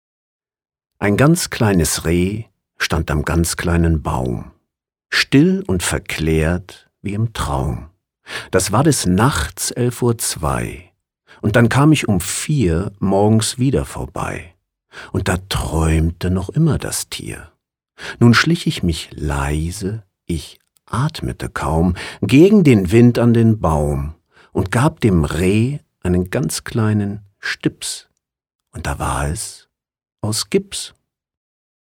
Professioneller deutscher Sprecher / voice-over.
Sprechprobe: Sonstiges (Muttersprache):
professional voice over, german speaking narrator (voice over, dubbing actor, video games, audio book, radio drama, docoumentary, advertising, poetry etc.).
Gedicht-Ringelnatz.mp3